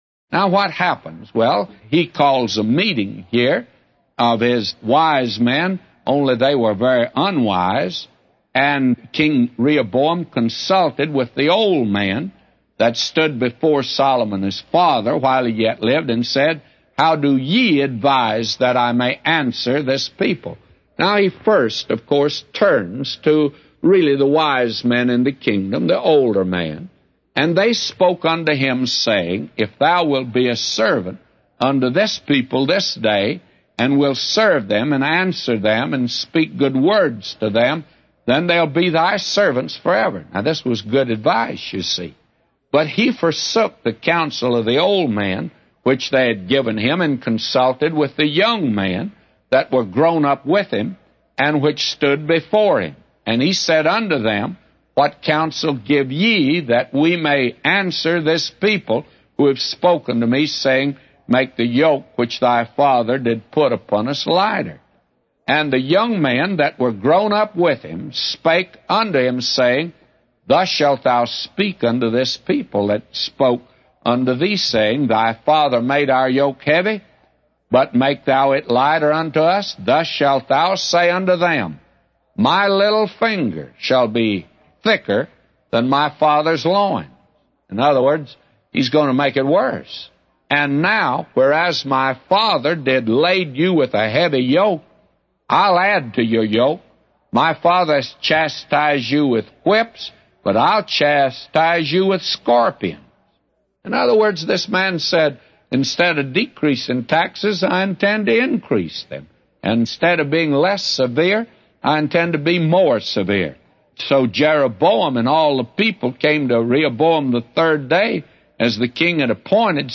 A Commentary By J Vernon MCgee For 1 Kings 12:6-999